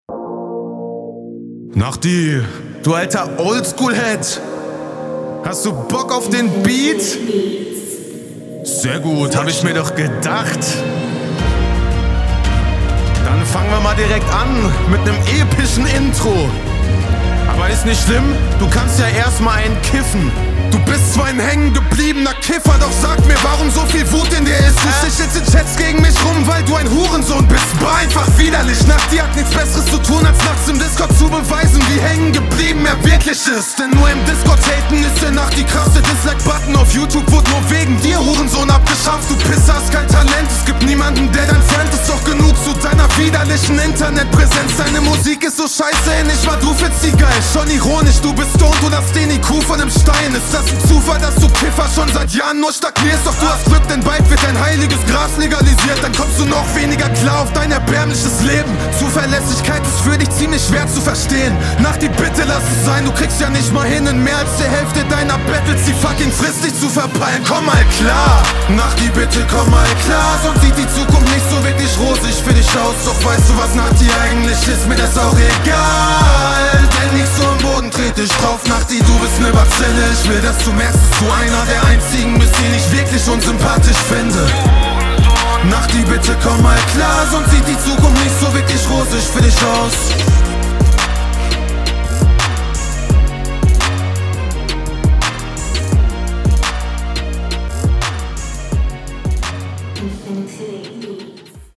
hätte mir flowlich bisschen mehr variation gewünscht, das klingt zwar alles super schön und druckvoll …
sehr fetter Sound wie immer, krass gerappt und Atmo cool.